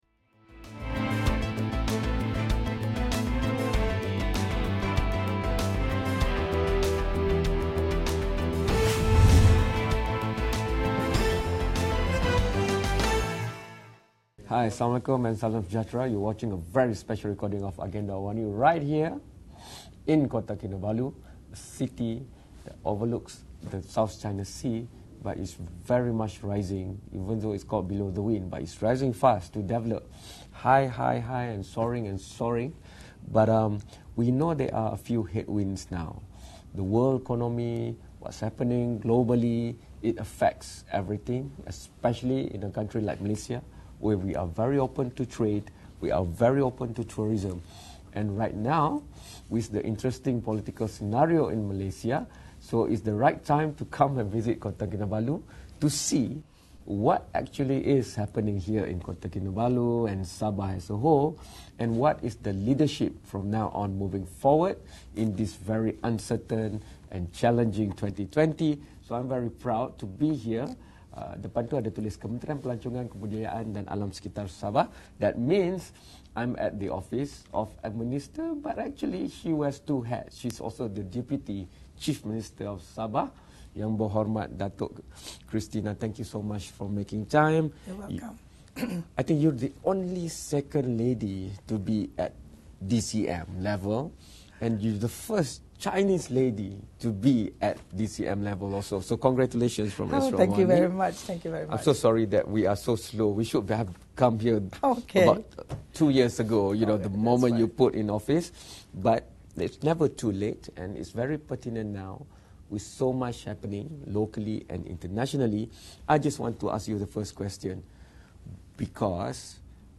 We speak to Deputy Chief Minister of Sabah, Datuk Christina Liew as well the State Minister of Tourism, Culture and Environment of Sabah on Sabah’s tourism sector, which has been the hardest hit by the COVID-19 pandemic